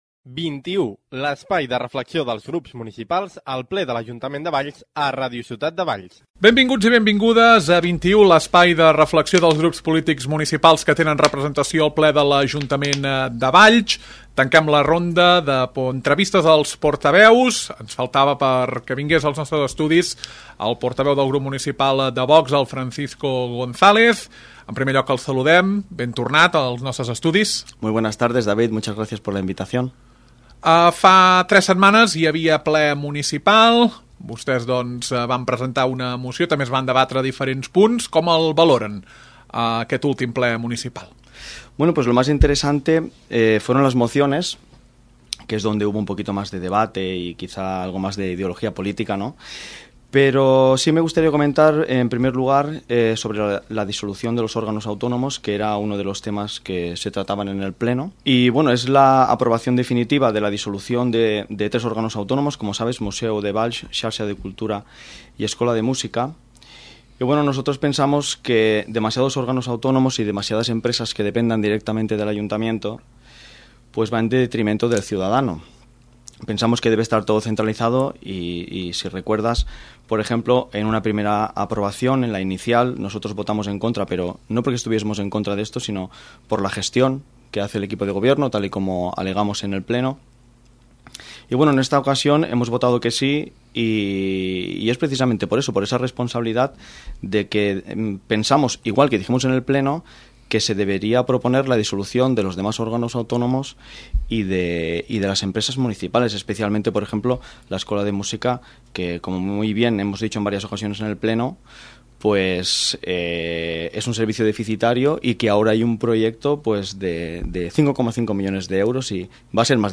Nova temporada de 21, l’espai de reflexió dels grups municipals que tenen representació al ple de l’Ajuntament de Valls. Ronda d’entrevistes als diferents portaveus de major a menor proporció a la sala de plens. Avui és el torn de Francisco González de VOX.